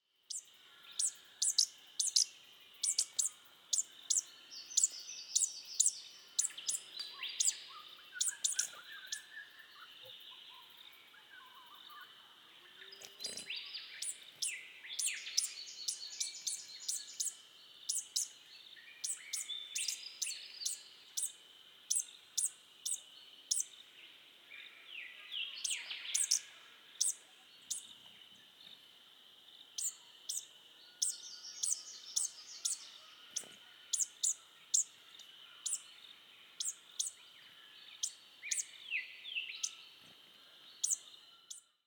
The song of the Superb Fairy-wren is a fast, high-pitched reeling series of notes and is given by both males and females.
Superb Fairywren – Typical calls
XC172262 Typical high-pitched contact calls. Recorded in Glen Davis, NSW, Australia.
superb-fairy-wren-1.mp3